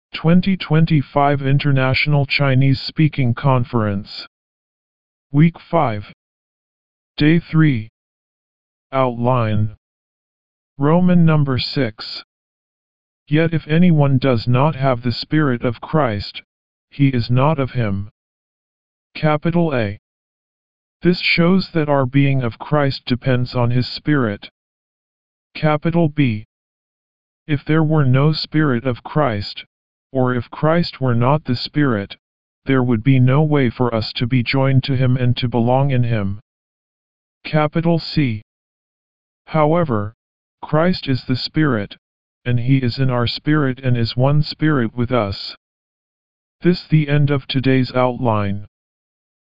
Morning Revival Recitation